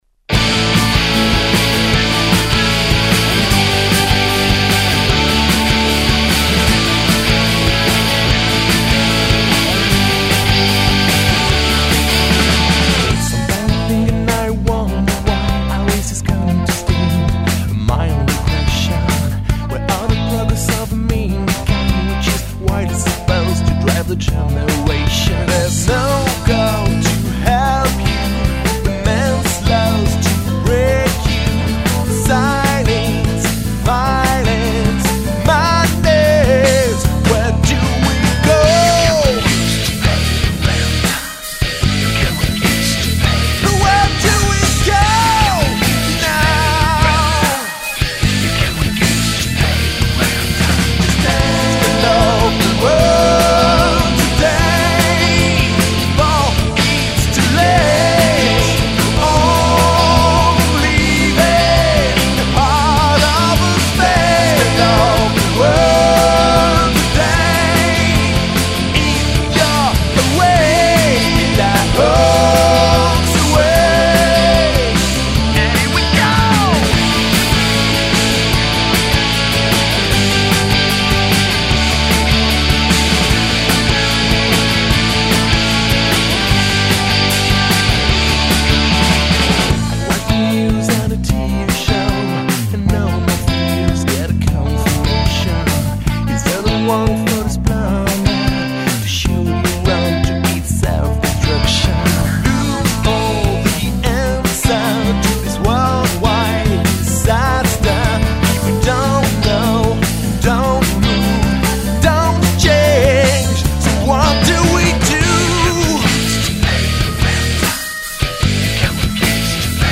enregistré entre juin 2001 et février 2002, au studio
cet album sonne comme une bombe !
guitares
voix
claviers
basse
batterie